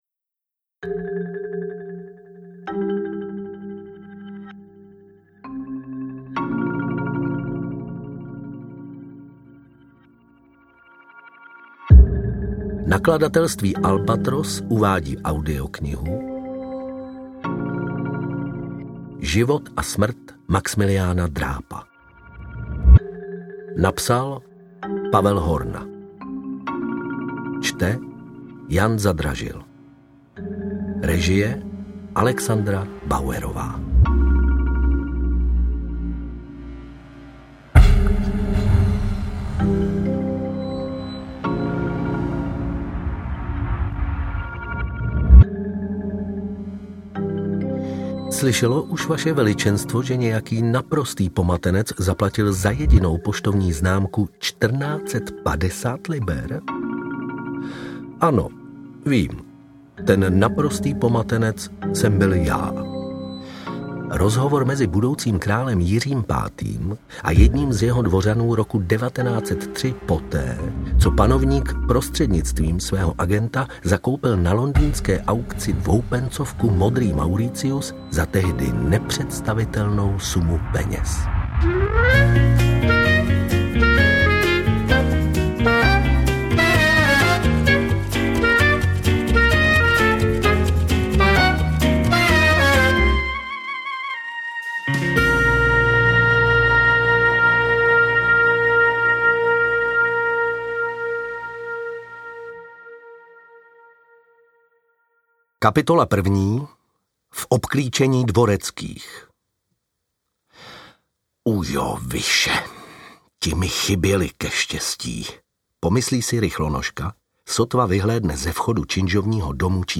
Interpret/Interpretka